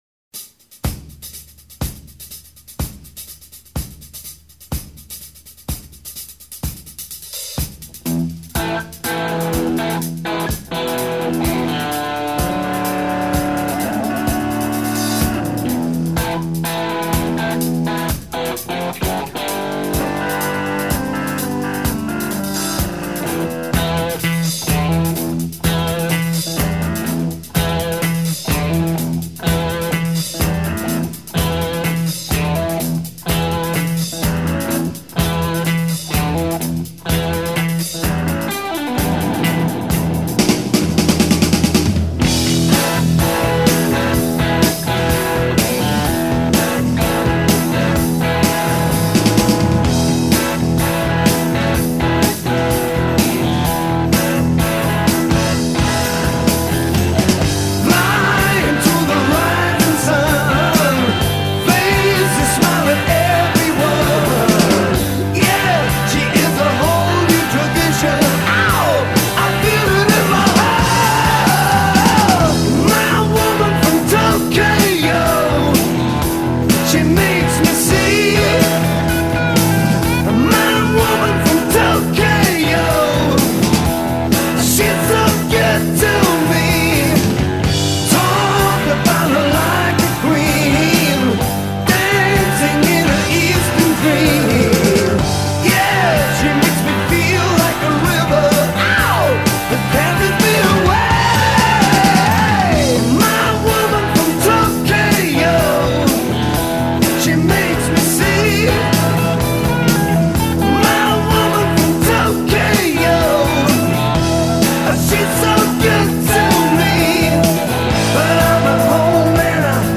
Hard Rock, Progressive Rock, Blues Rock